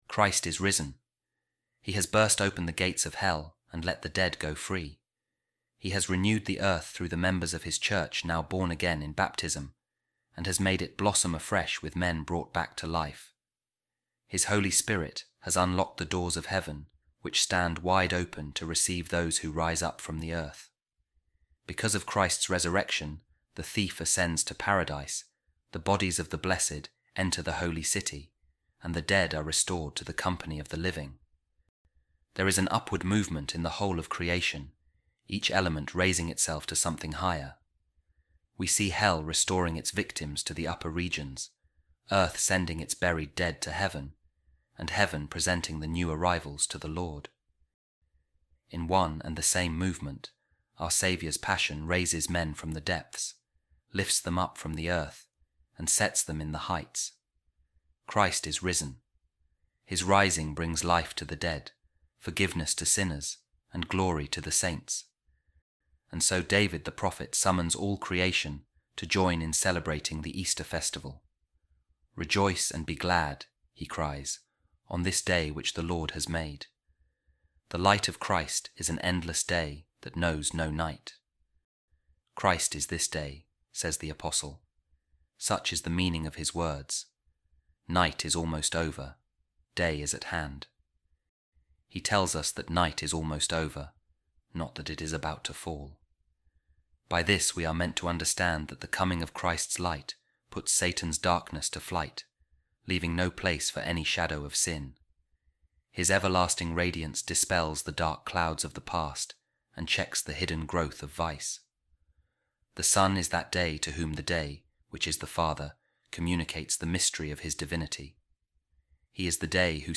A Reading From The Sermons Of Saint Maximus Of Turin | Jesus Christ Is The Day